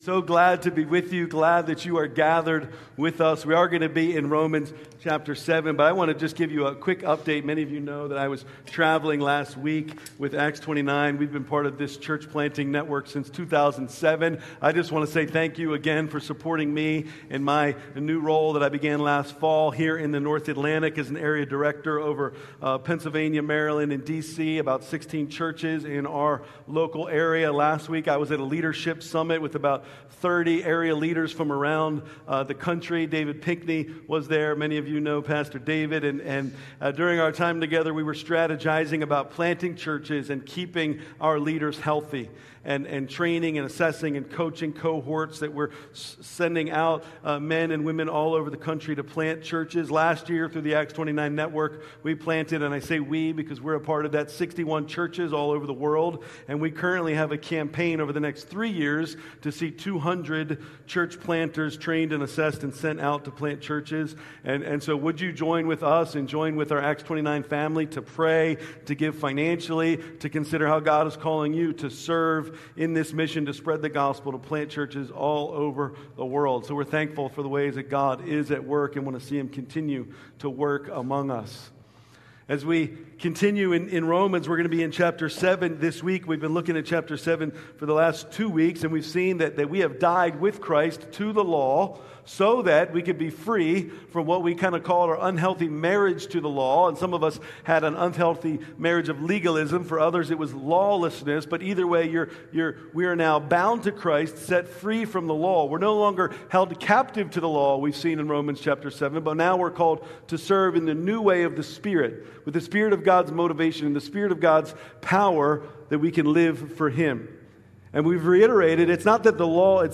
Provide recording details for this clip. January 24, 2026 Worship Service Livestream recorded Saturday due to Sunday's forecasted weather .